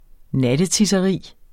Udtale [ ˈnadəˌtisʌˌʁiˀ ]